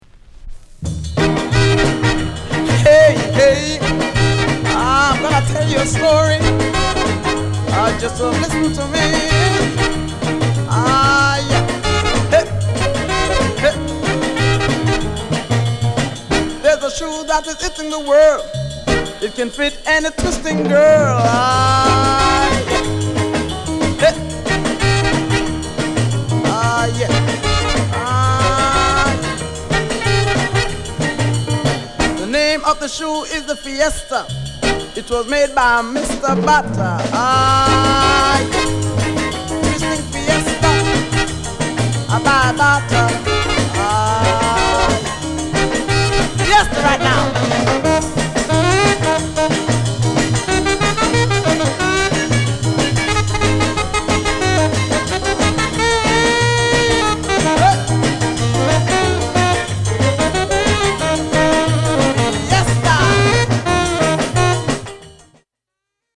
CALYPSO